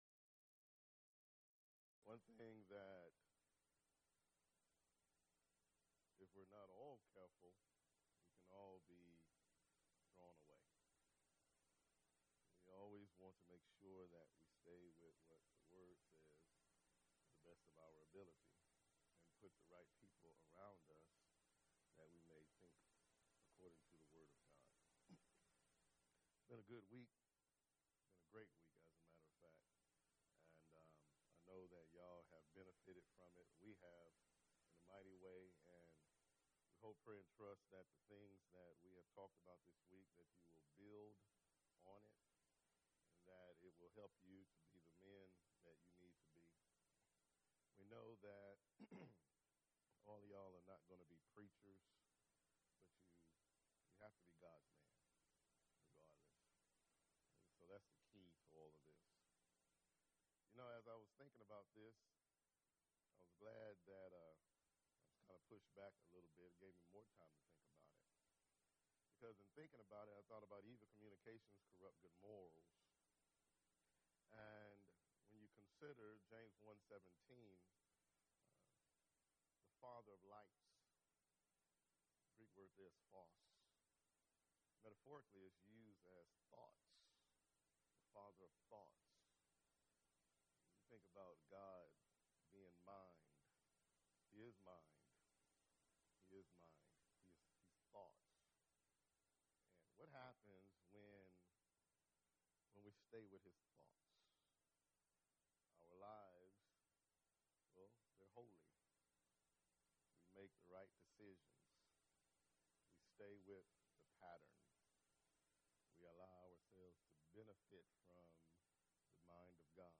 Event: 1st Annual Young Men's Development Conference
lecture